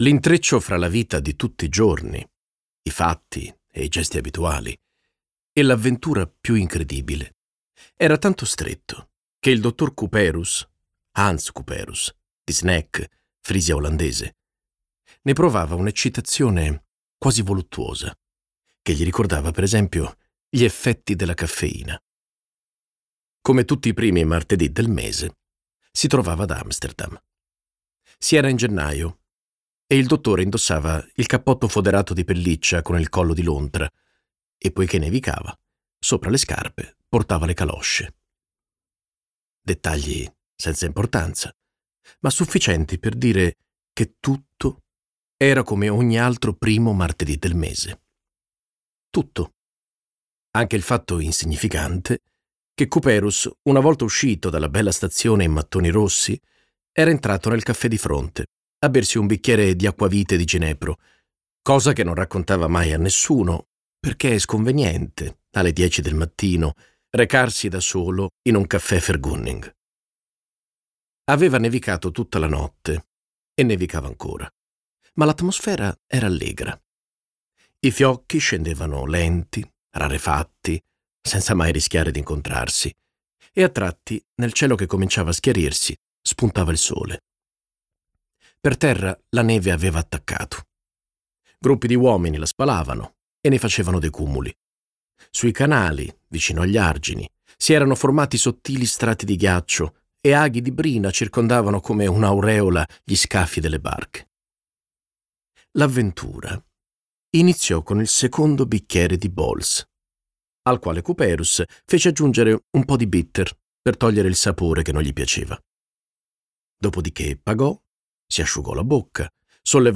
Versione audiolibro integrale